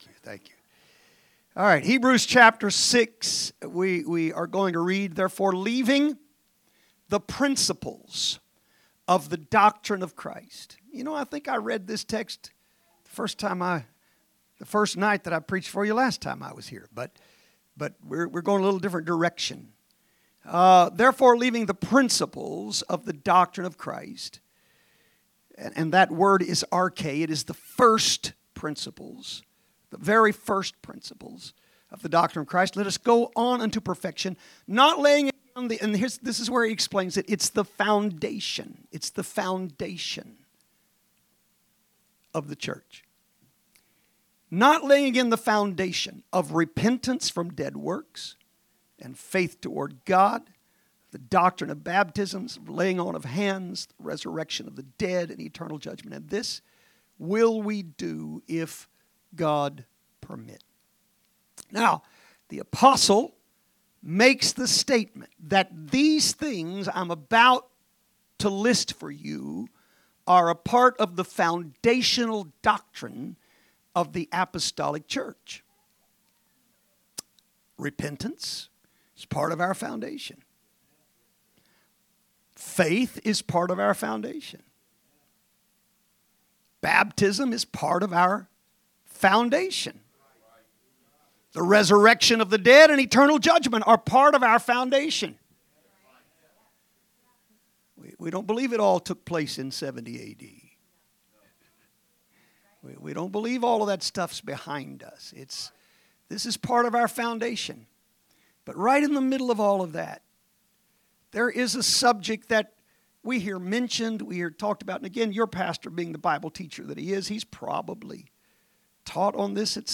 Wednesday Message - Revival